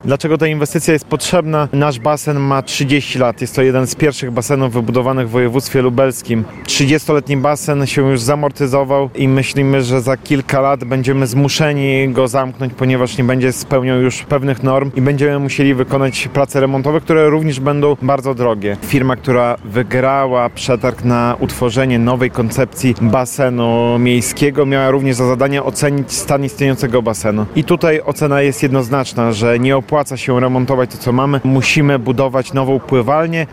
– Wstępny kosztorys budowy obiektów wynosi 70 mln złotych – mówi w rozmowie z Radiem Lublin zastępca burmistrza Łukowa Mateusz Popławski.